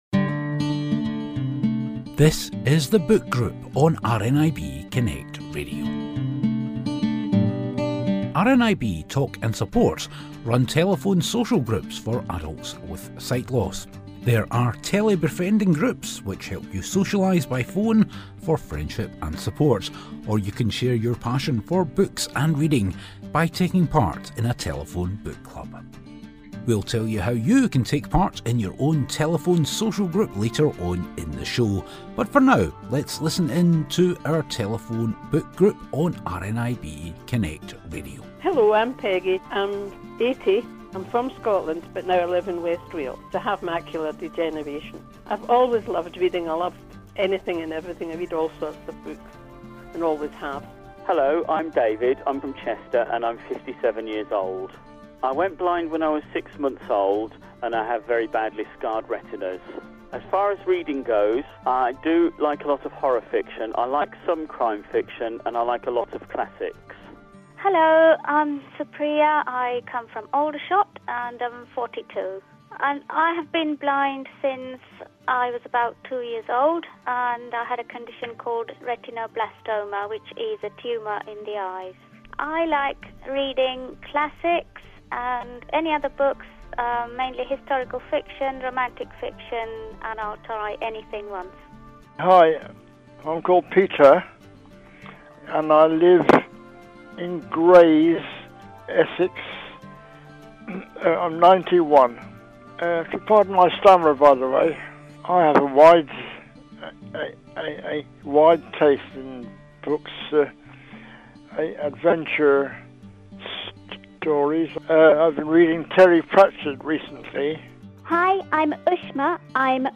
This month our blind and partially sighted book group discuss Eleanor Oliphant is Completely Fine by Gail Honeyman.